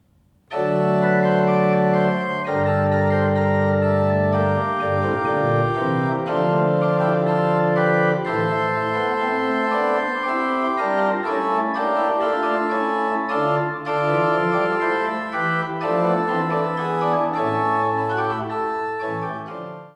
Bourdon 16'
Montre 8'
Soubasse 16'